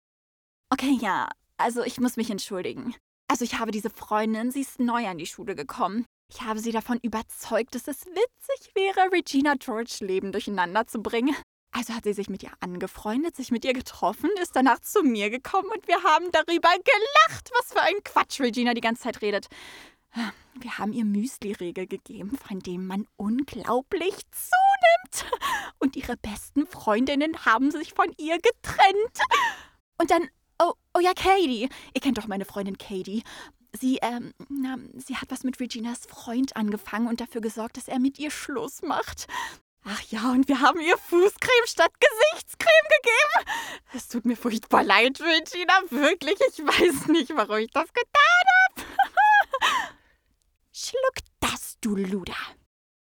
Deutsch-russische Sprecherin mit Gesangserfahrung, ehemalige Solistin im jungen Ensemble des Friedrichstadt Palast Berlin; Schubert Schauspielmanagement
Sprechprobe: Sonstiges (Muttersprache):